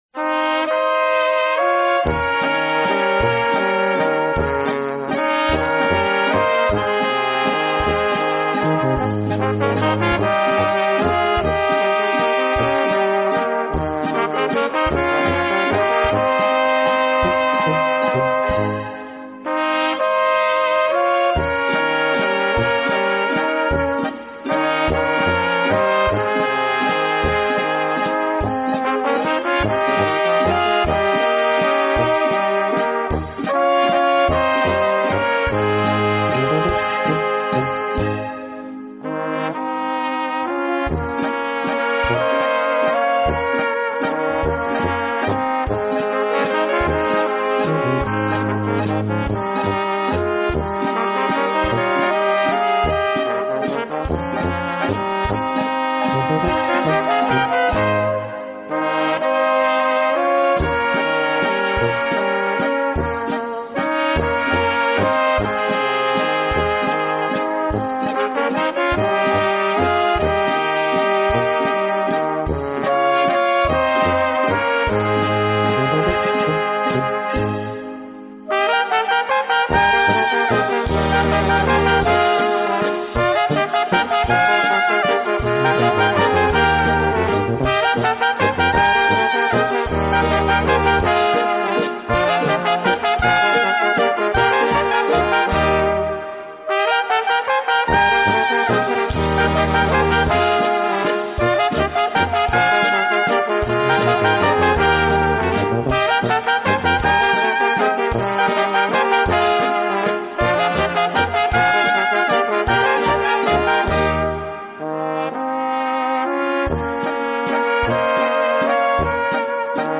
Gattung: Walzer
Besetzung: Blasorchester
als Arrangement für großes Blasorchester